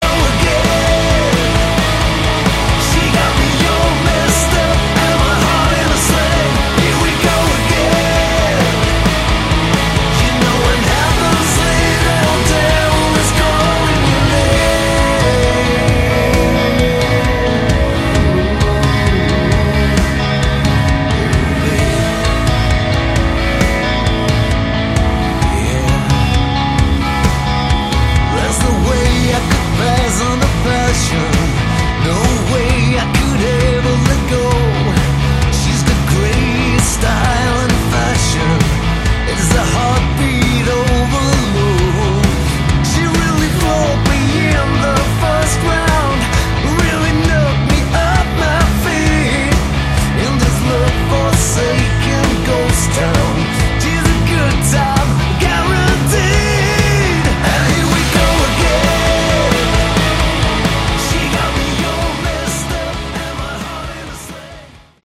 Category: Hard Rock
drums
guitars
bass
vocals
keyboards